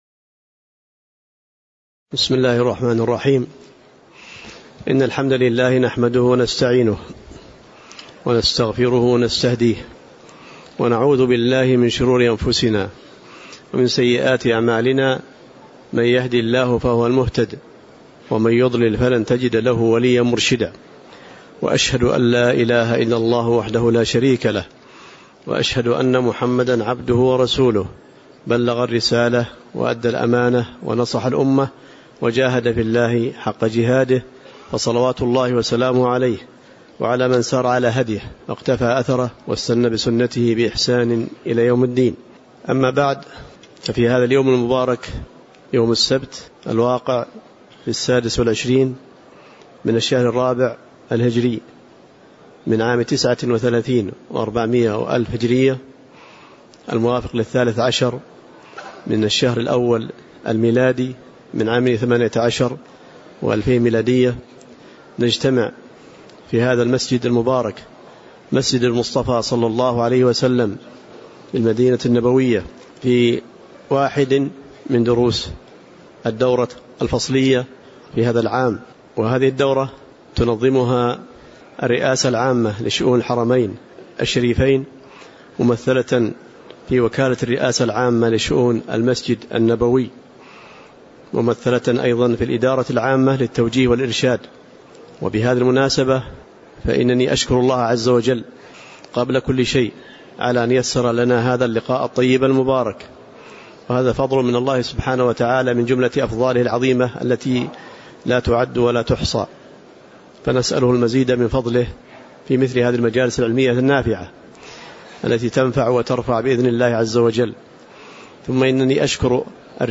تاريخ النشر ٢٦ ربيع الثاني ١٤٣٩ هـ المكان: المسجد النبوي الشيخ